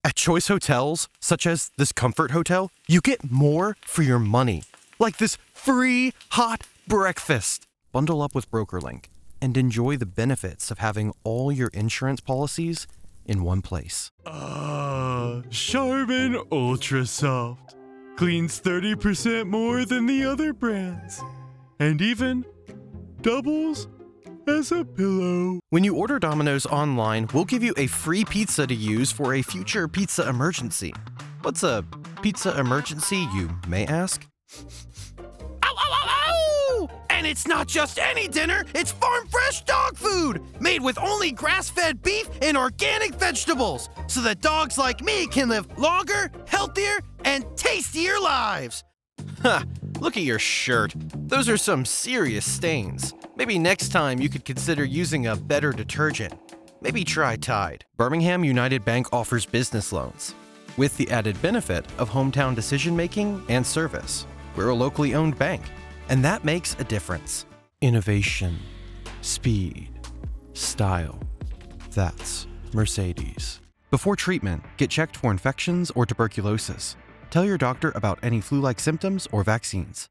My voice is best is best described as youthful, energetic, animated, and friendly. My equipment include: Neumann TLM 103, Scarlett Focusrite Solo 3rd Gen, Reaper DAW, and a custom built accousticaly treated studio.
English - Midwestern U.S. English
Young Adult
Commercial